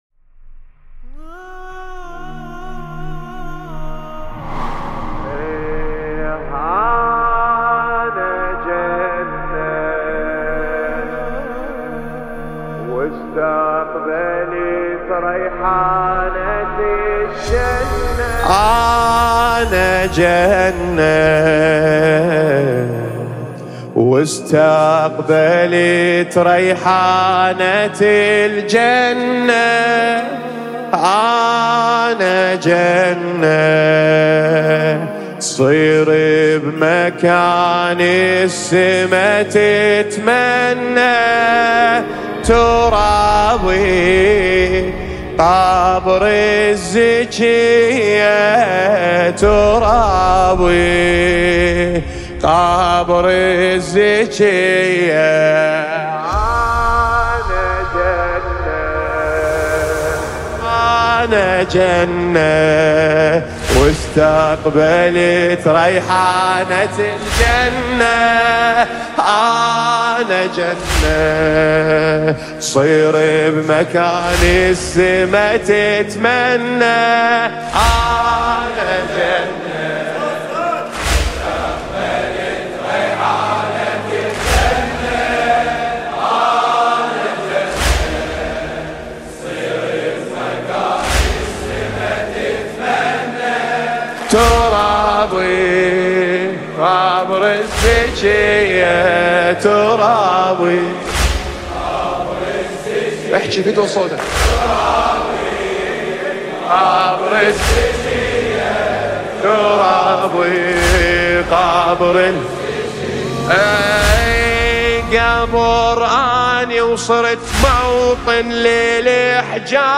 مداحی عربی دلنشین